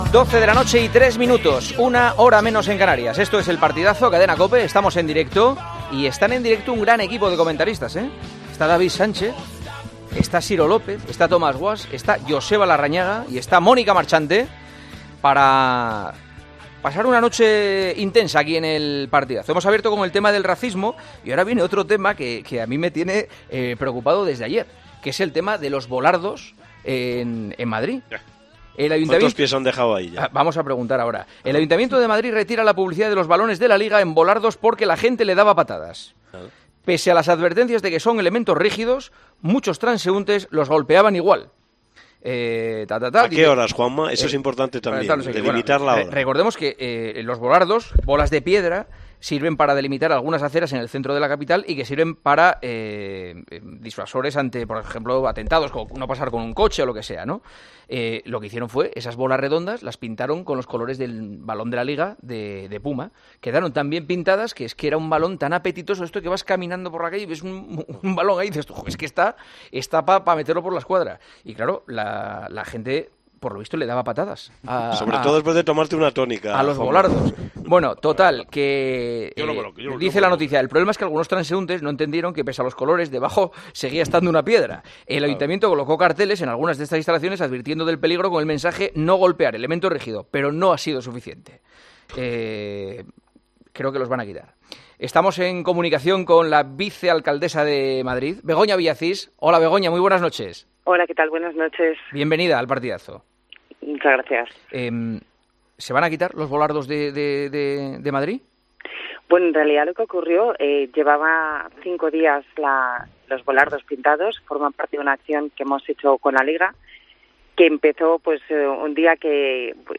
La vicealcaldesa de Madrid habla en El Partidazo de COPE sobre la retirada de los bolardos decorados como pelotas de fútbol.